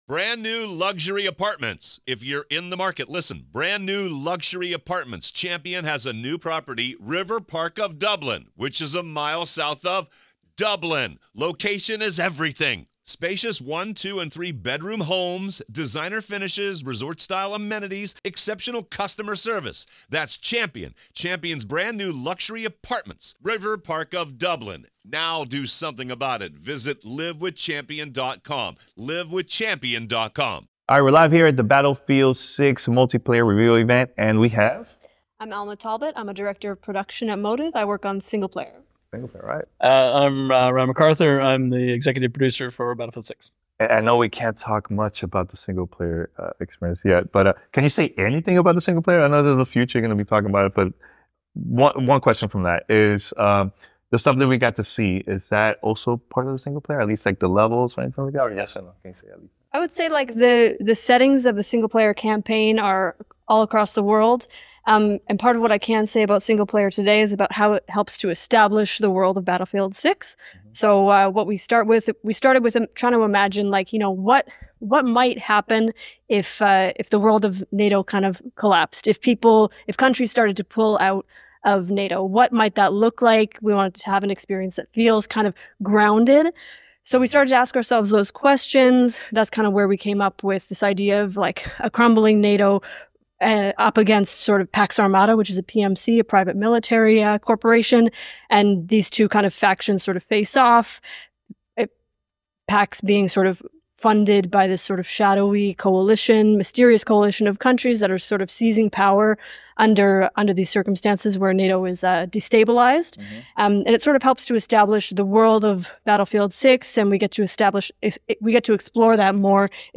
Battlefield 6 Developer Interview